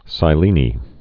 (sī-lēnī)